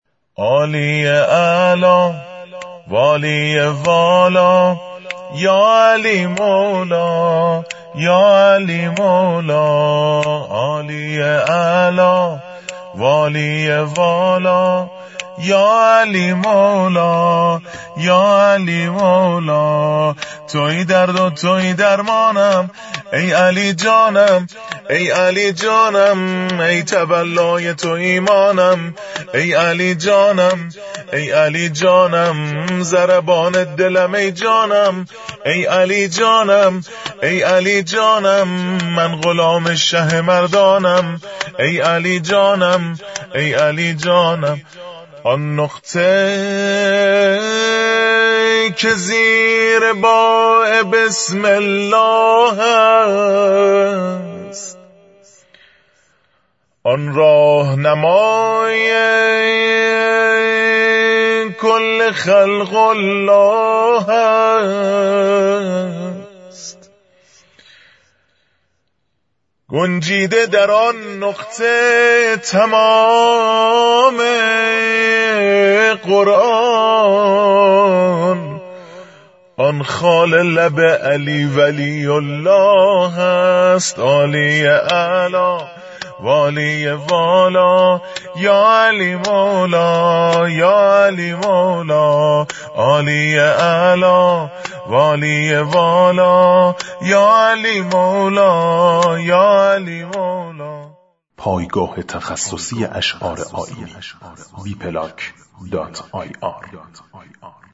عید غدیر
سرود